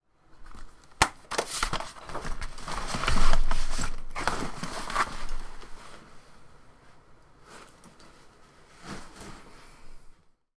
computer-pack-away.wav